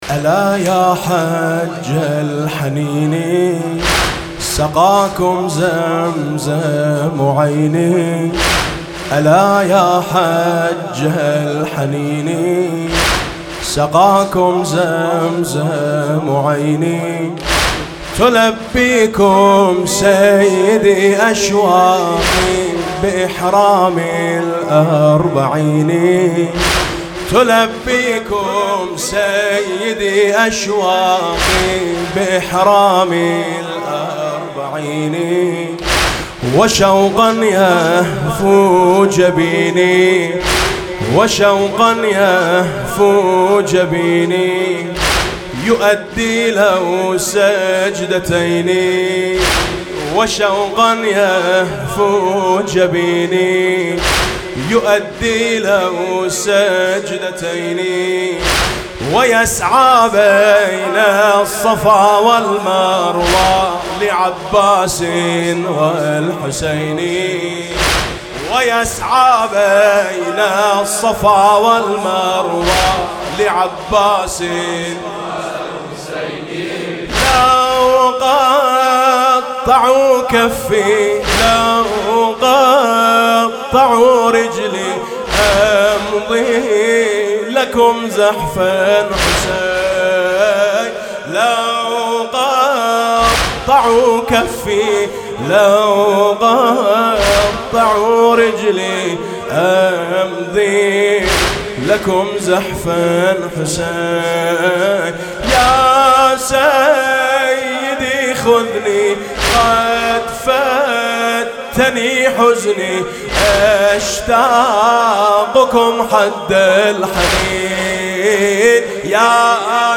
لطميات-الكوثر